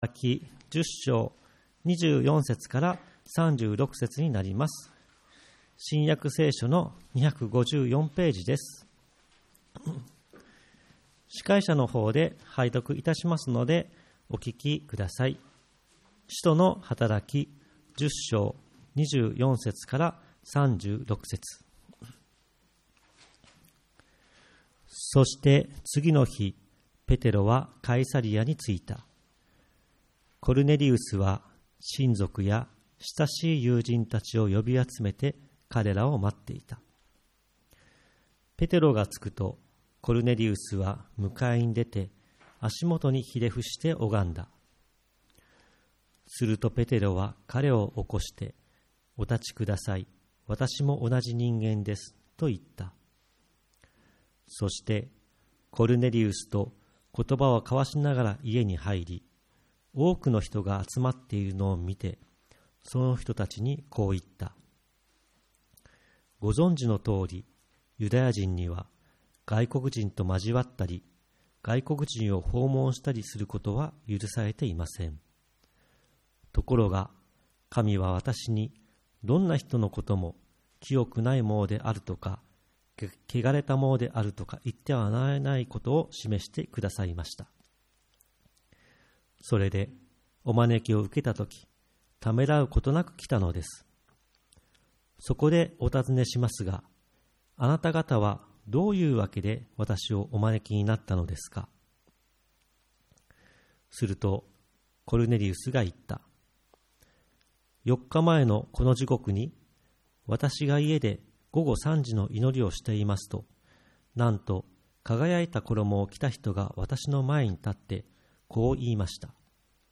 2024.4.7 主日礼拝
イースター礼拝